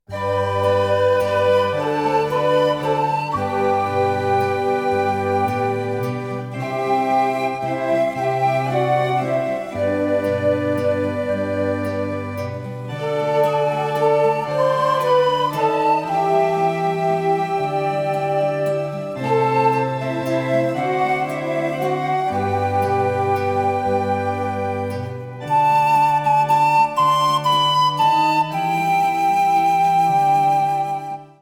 Erhältlich mit Solo / Playback Double CD